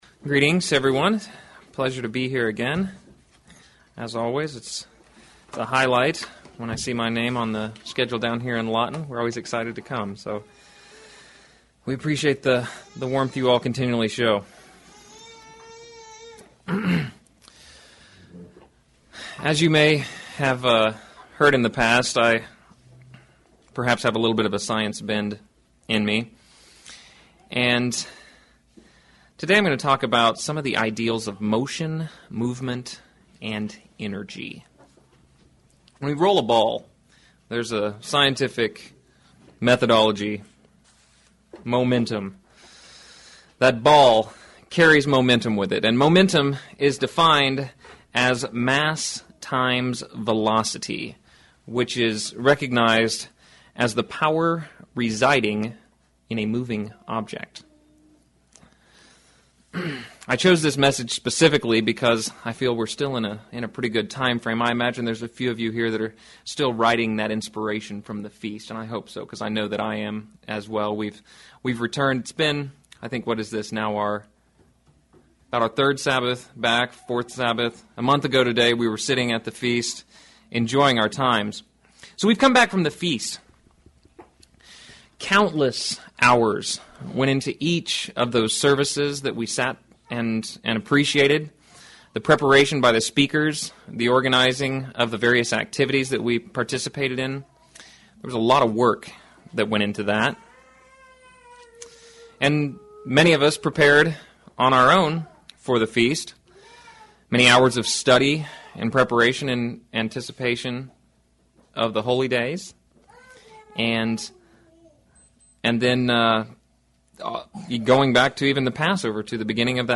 Given in Lawton, OK
UCG Sermon Studying the bible?